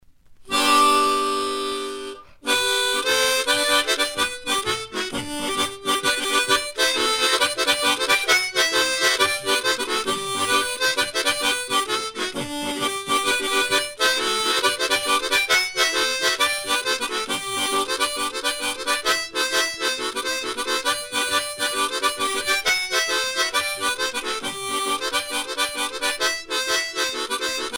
Chants de marins traditionnels
Pièce musicale éditée